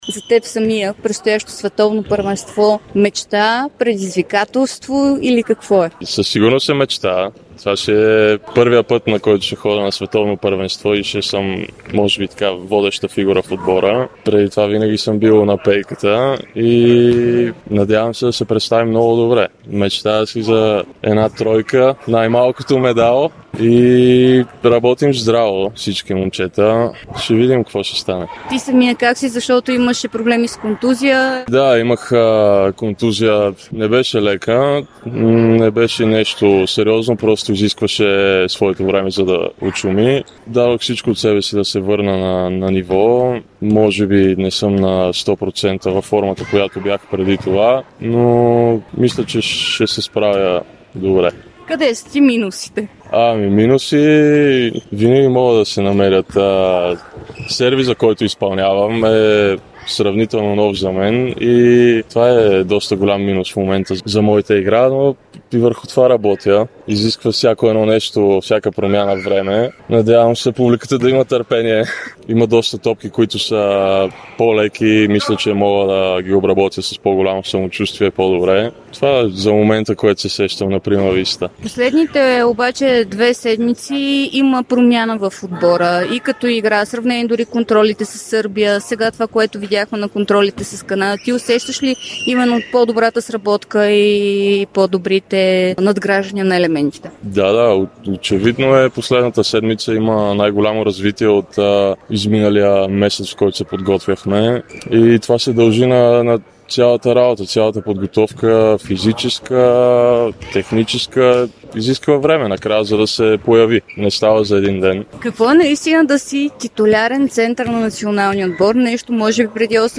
Алекс Грозданов говори специално пред Дарик радио и dsport за предстоящото Световно, за мечтите си, за уроците и любовта към волейбола.